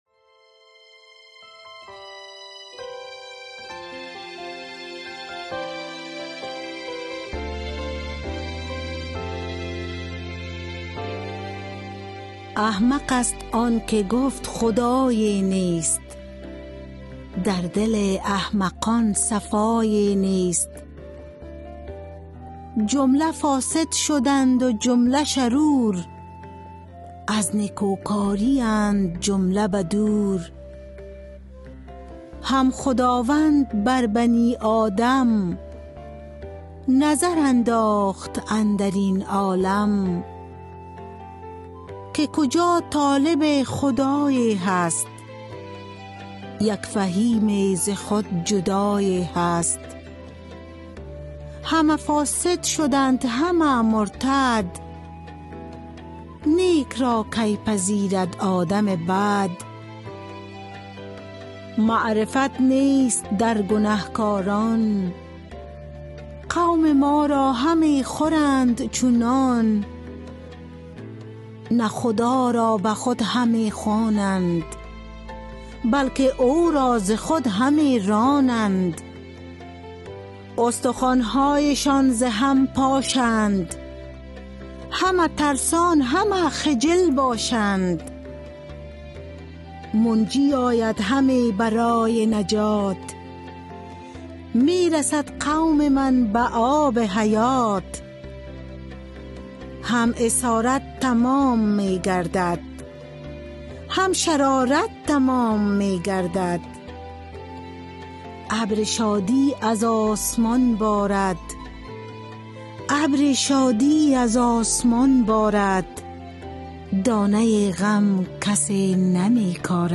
Poem Psalm 53